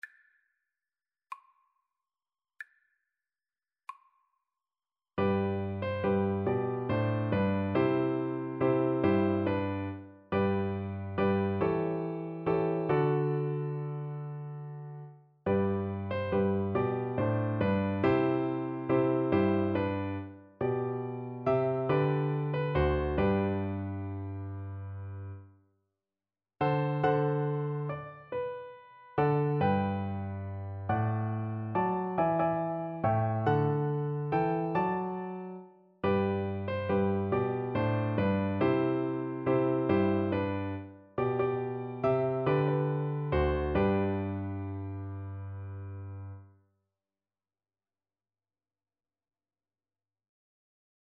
Christian
6/8 (View more 6/8 Music)
Classical (View more Classical Cello Music)